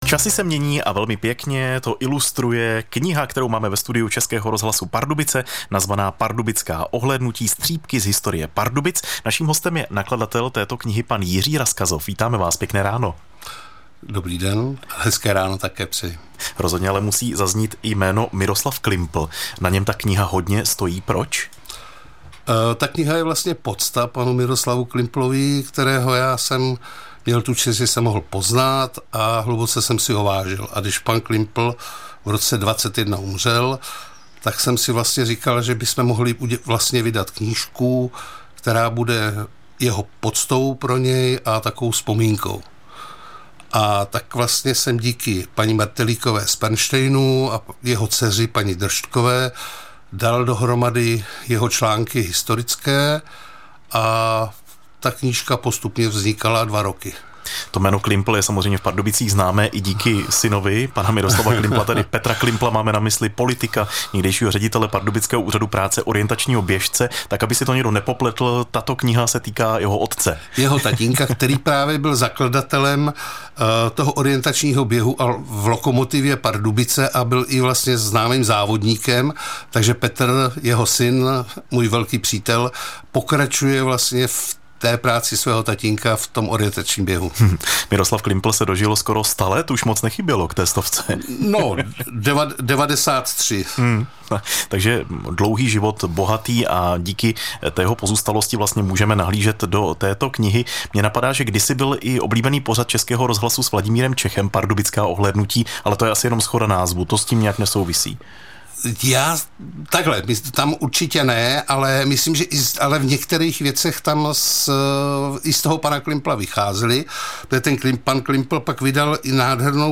Zprávy pro Pardubický kraj: Silnice přes křižanovickou přehradu je zase průjezdná.